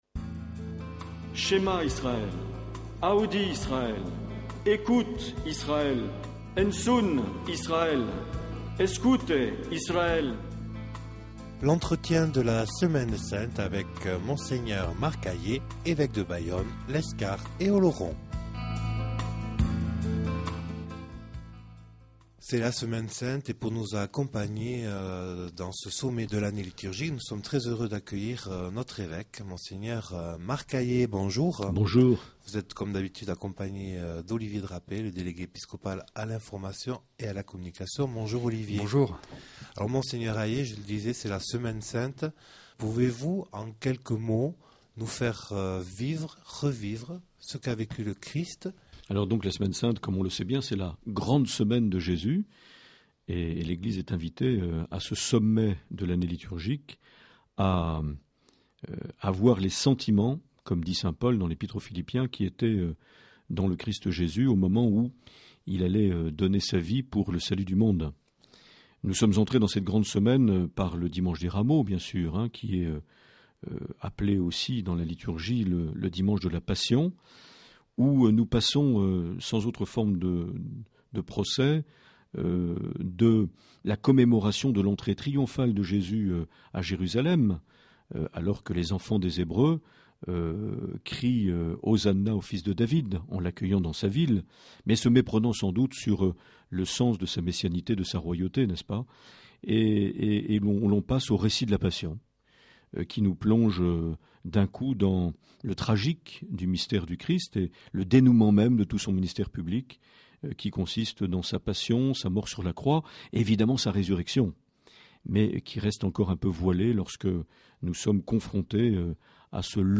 Une émission présentée par Monseigneur Marc Aillet